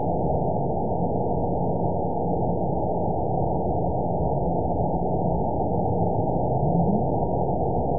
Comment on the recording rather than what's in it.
event 911340 date 02/22/22 time 14:12:52 GMT (3 years, 2 months ago) score 9.14 location TSS-AB02 detected by nrw target species NRW annotations +NRW Spectrogram: Frequency (kHz) vs. Time (s) audio not available .wav